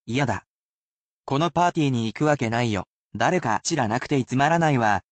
[casual speech]